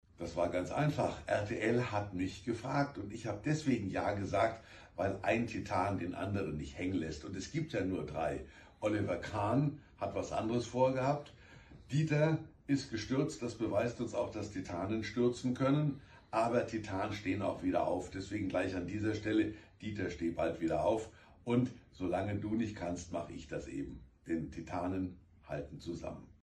DSDS-Gottschalk-Interview.mp3